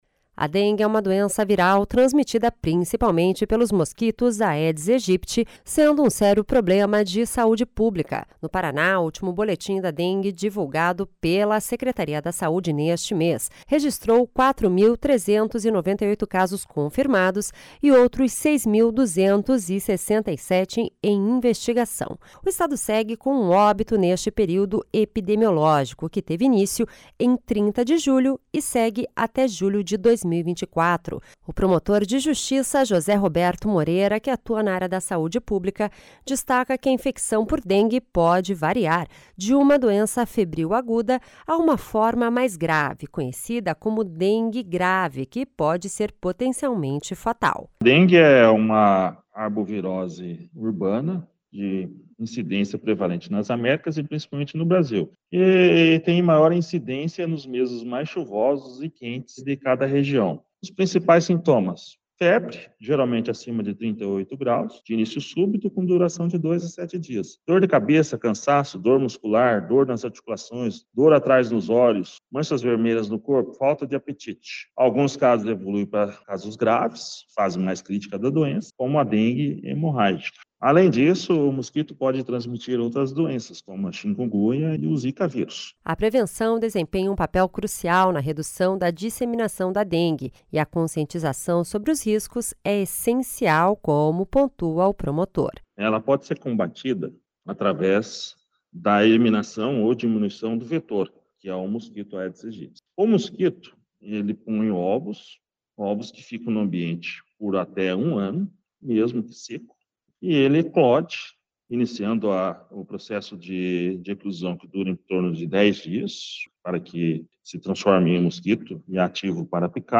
O promotor fala sobre como denunciar esses casos.